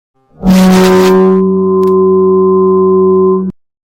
Warning 3 Bouton sonore